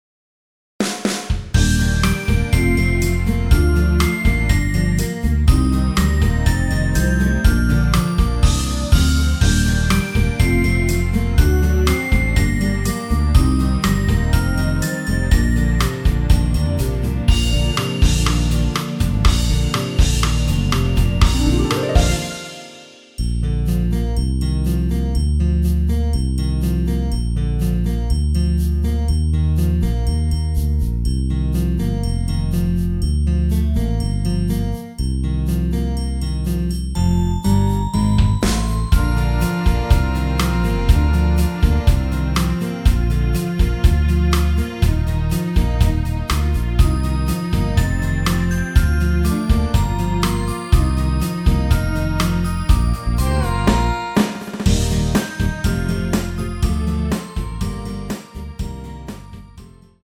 원키에서(-6)내린 MR입니다.
Bb
앞부분30초, 뒷부분30초씩 편집해서 올려 드리고 있습니다.
중간에 음이 끈어지고 다시 나오는 이유는